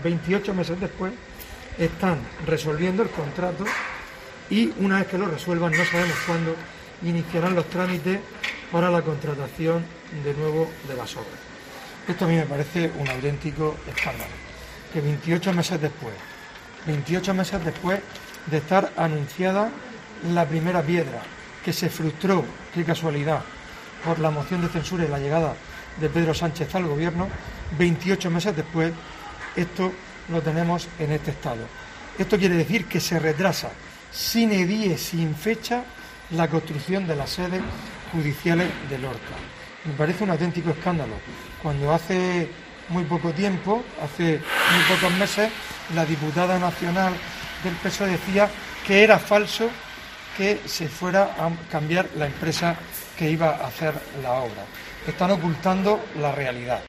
Fulgencio Gil, portavoz del PP sobre Palacio de Justicia